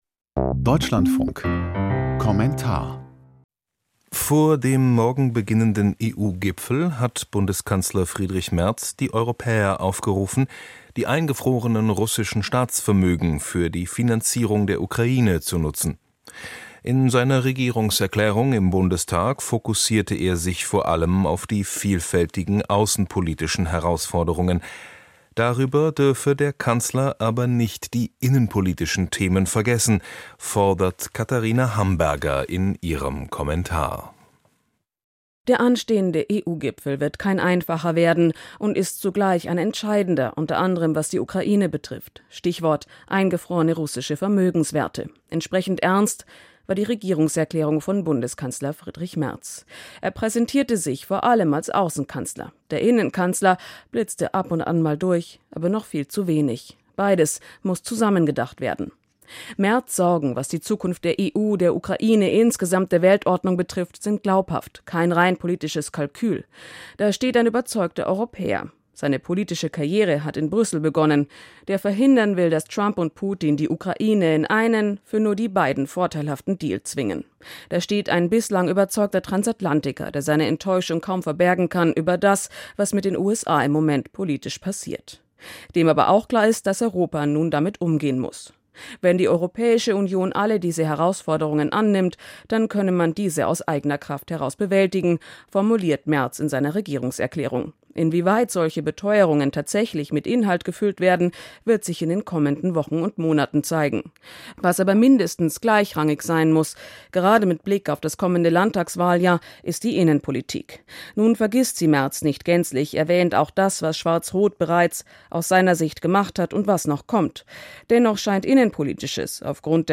Kommentar zur Regierungserklärung des Kanzlers: Innenpolitisch zu wenig präsent